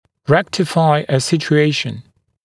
[‘rektɪfaɪ ə ˌsɪʧu’eɪʃn] [‘рэктифай э ˌсичу’эйшн] исправить ситуацию